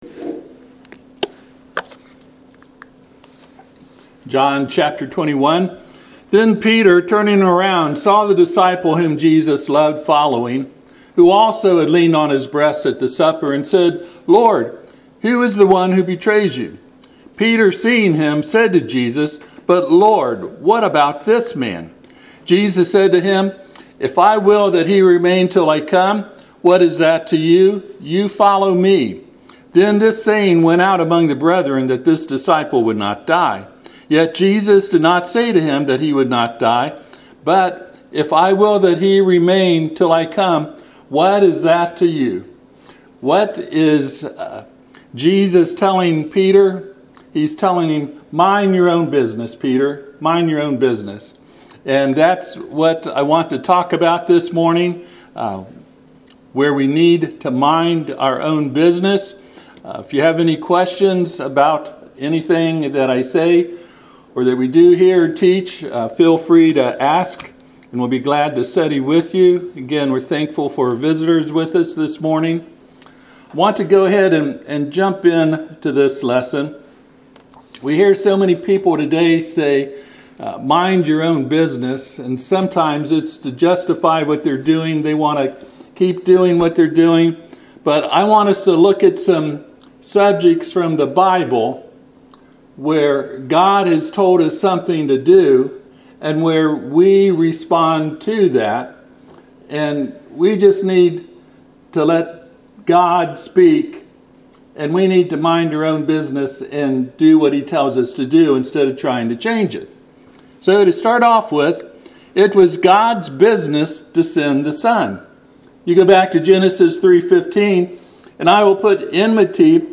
Passage: john 21:20-23 Service Type: Sunday AM